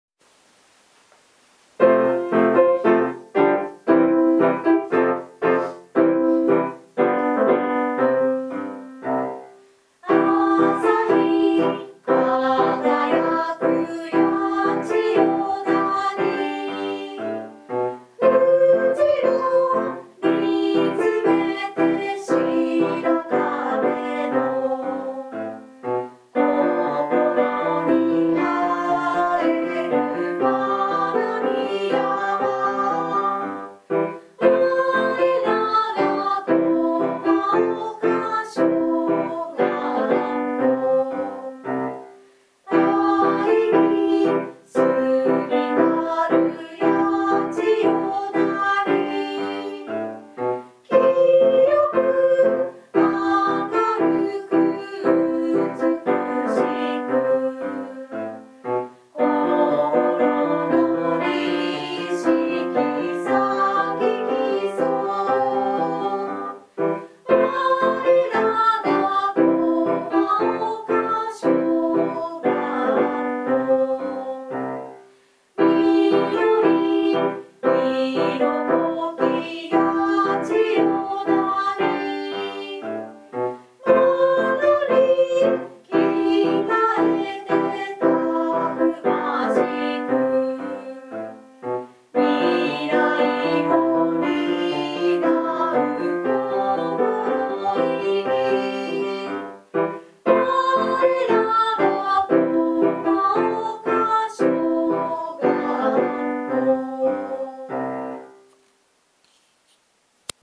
校歌
校歌（歌） [2588KB MP3ファイル]
校歌（音声ファイル：歌入り）